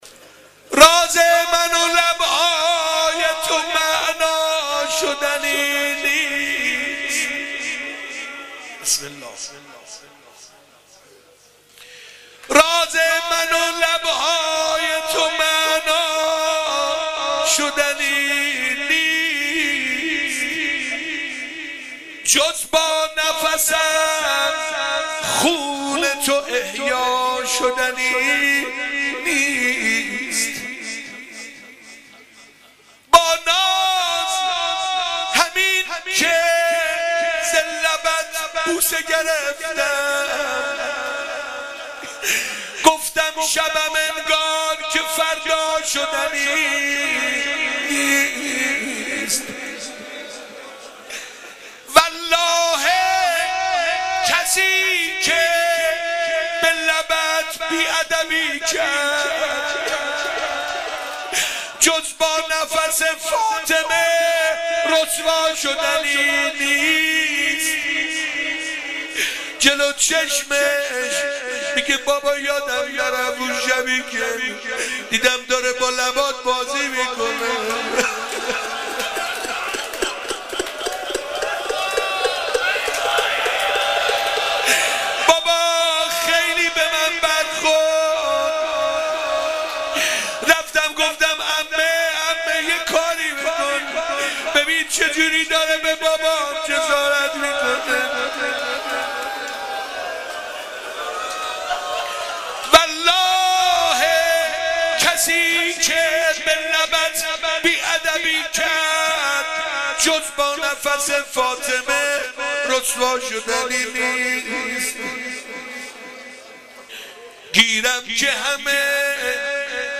شب سوم محرم 95_غزل و روضه_غزل و روضه حضرت رقیه(س)